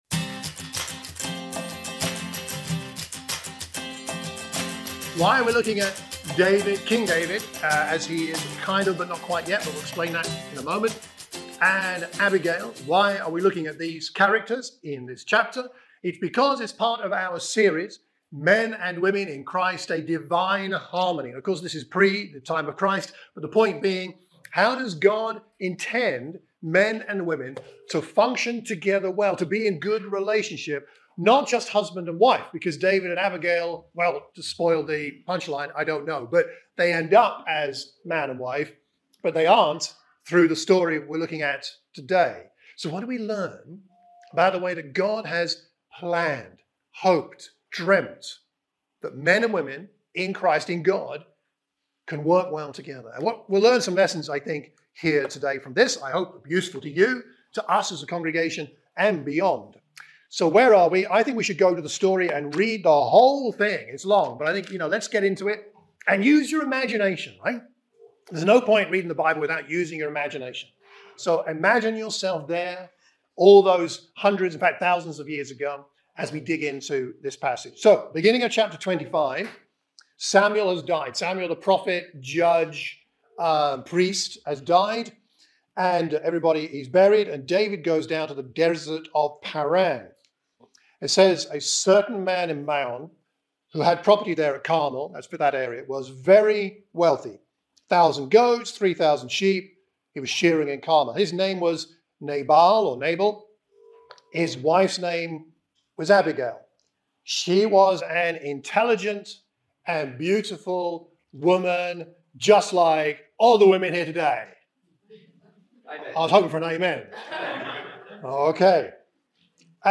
A sermon for the Watford church of Christ